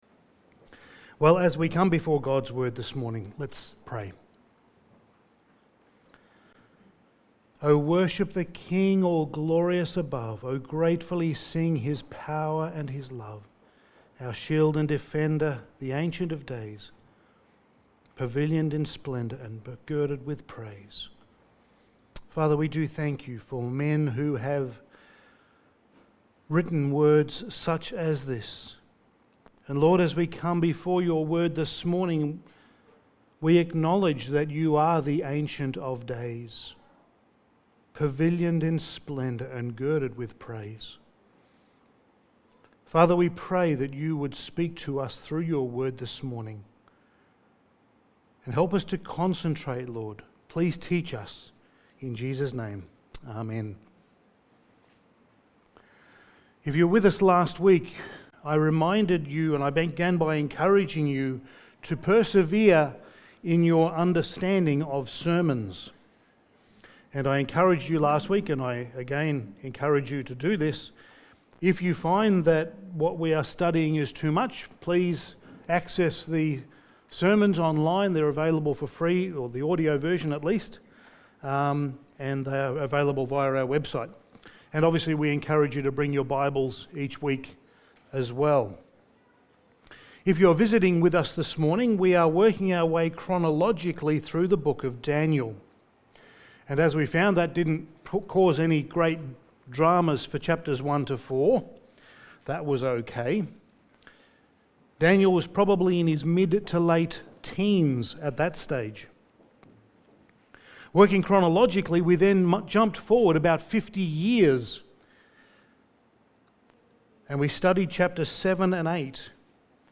Passage: Daniel 5:1-12 Service Type: Sunday Morning